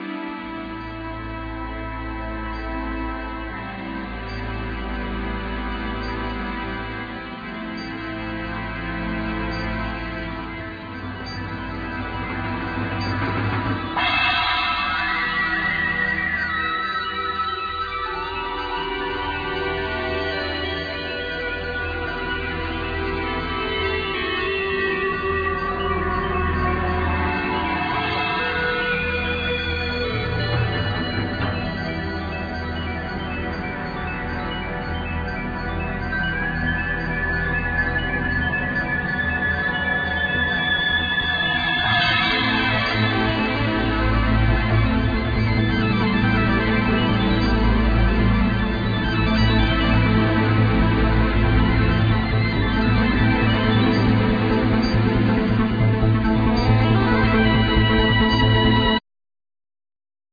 Piano,Synthsizer
Bamboo flute